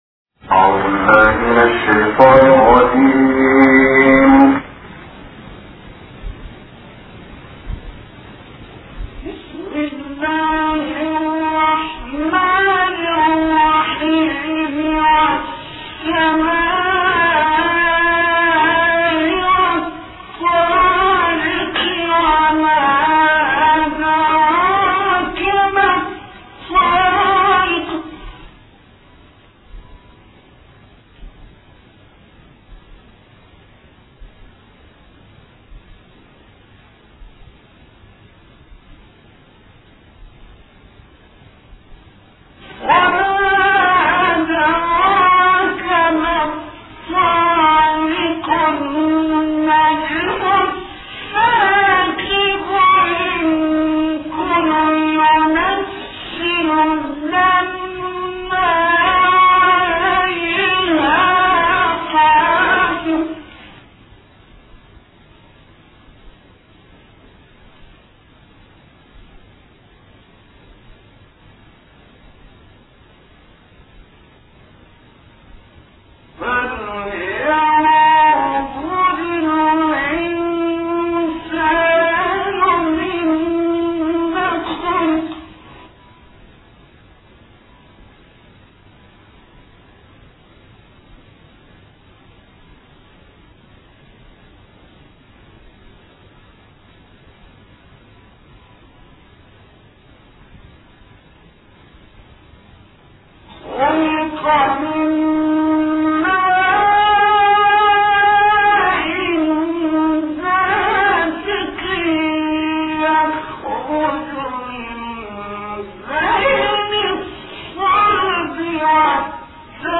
تلاوات قرآنية